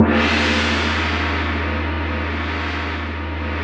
Index of /90_sSampleCDs/Roland LCDP03 Orchestral Perc/CYM_Gongs/CYM_Gongs Dry
CYM R8 GONG.wav